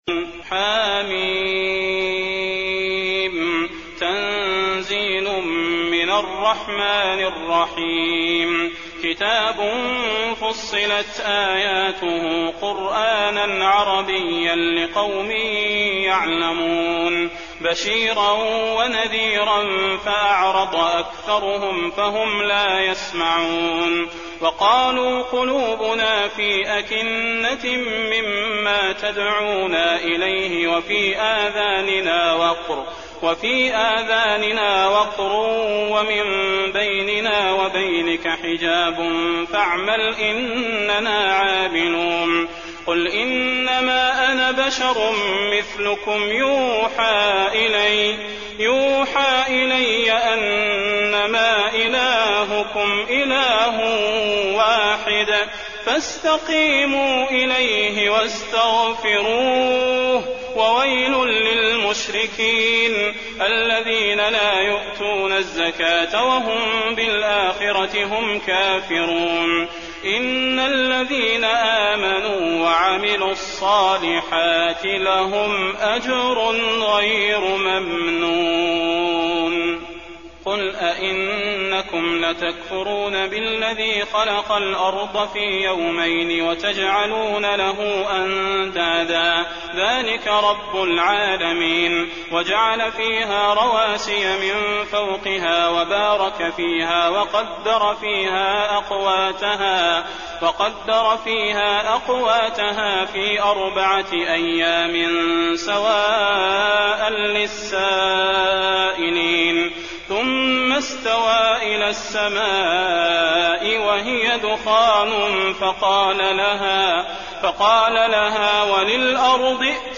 المكان: المسجد النبوي فصلت